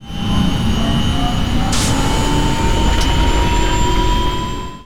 engine_start_002.wav